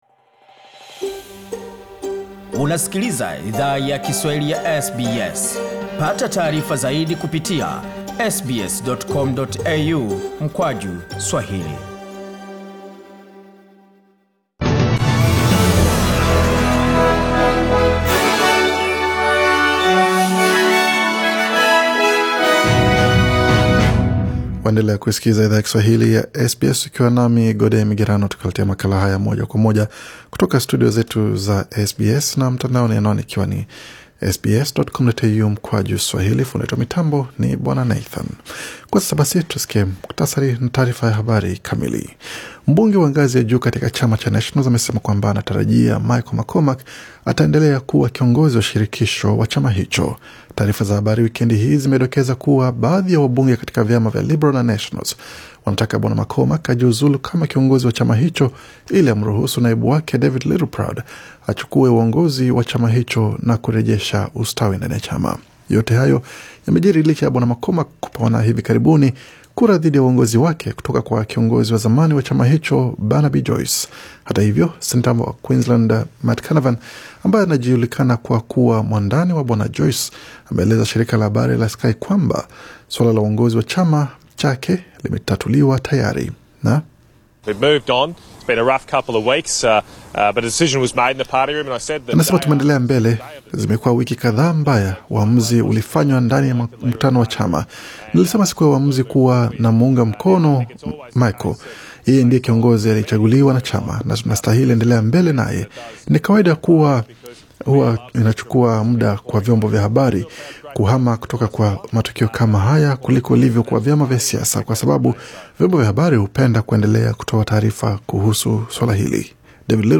Taarifa za habari za SBS Swahili Source: SBS News